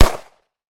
1-unload-var0.wav